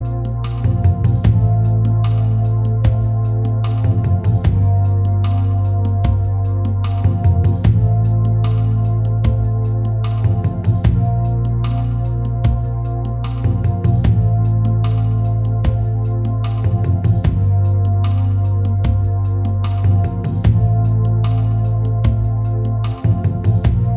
Minus All Guitars Rock 4:20 Buy £1.50